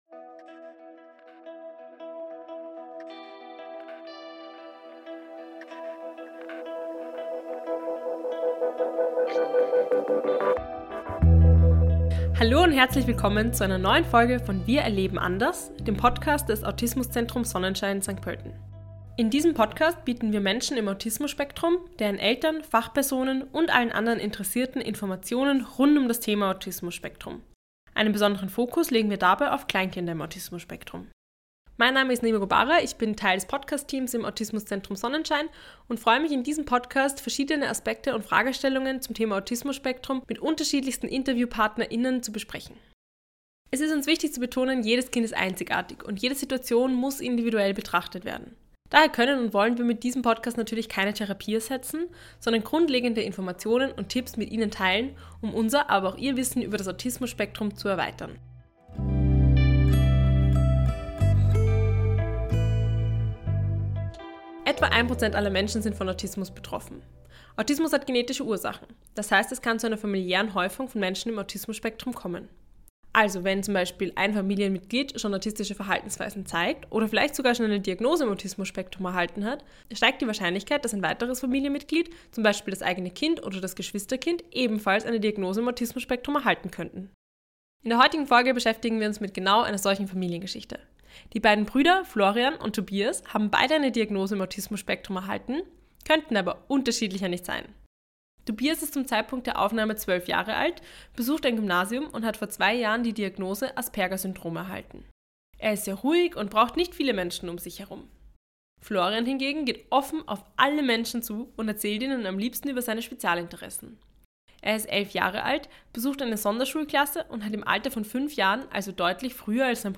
In dieser Folge sprechen wir mit der Mutter zweier Söhne im Autismus-Spektrum. Offen und ehrlich beantwortet Sie Fragen über ihr Familienleben, die Unterschiede und Gemeinsamkeiten ihrer Söhne, die Diagnostik und Therapien, sowie die Unterstützung, die die Familie erhalten hat oder sich noch wünschen würde.